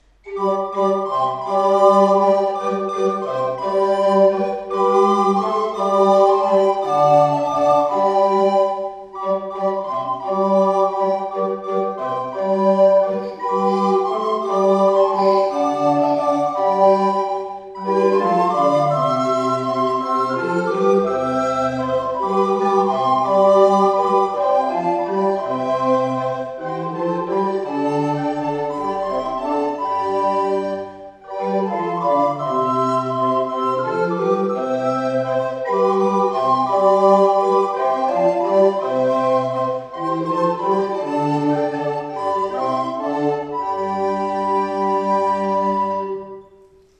Konzert-Aufnahme des Blockflötenensembles der